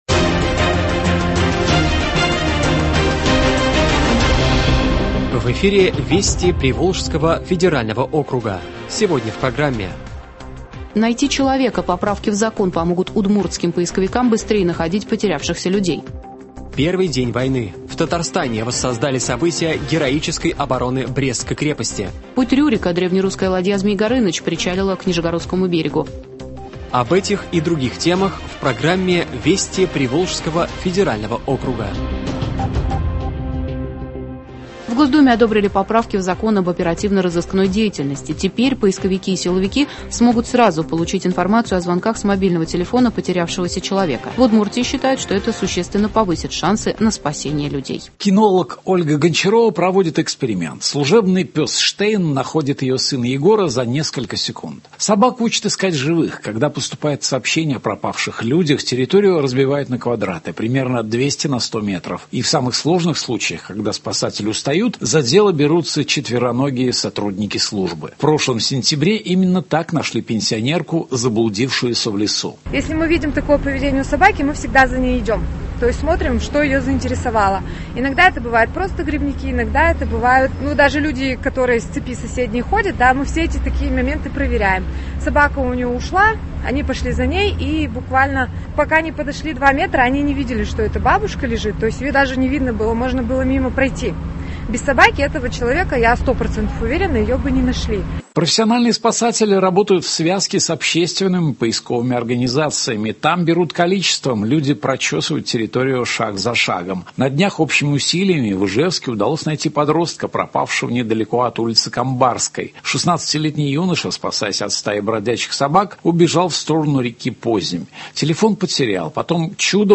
Радиообзор событий недели в регионах ПФО.